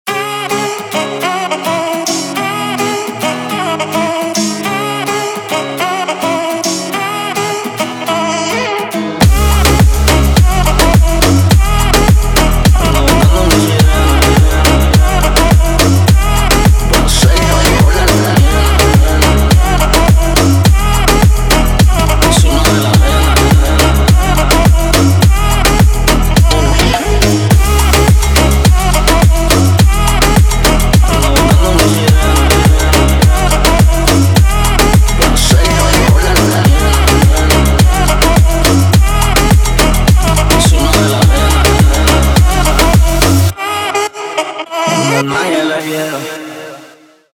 танцевальные
заводные